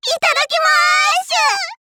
Kat and Anas' voice from the official Japanese site for WarioWare: Move It!
WWMI_JP_Site_Kat_Ana_Voice.wav